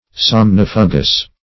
Search Result for " somnifugous" : The Collaborative International Dictionary of English v.0.48: Somnifugous \Som*nif"u*gous\, a. [L. somnus sleep + fugare to put to flight.] Driving away sleep.